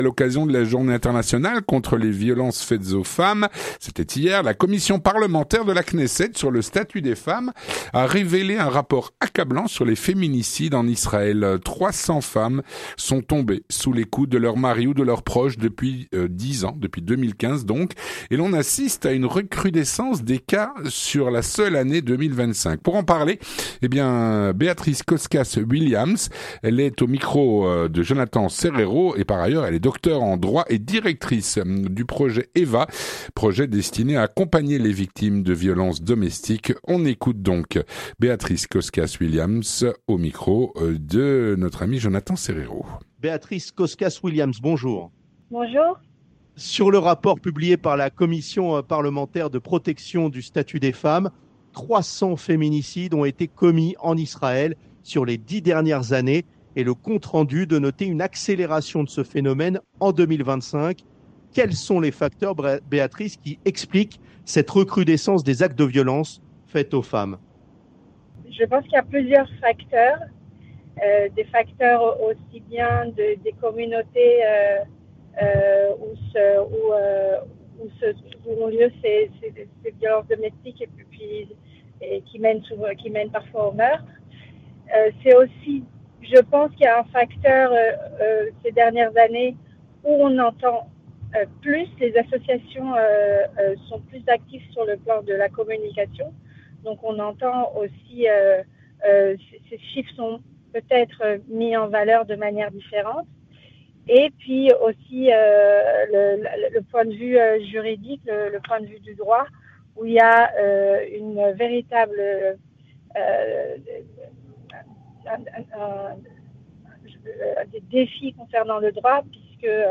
L'enttretien du 18H - En Israël, 300 femmes sont tombées sous les coups de leur mari ou de leurs proches depuis 2015.